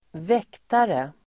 Uttal: [²v'ek:tare]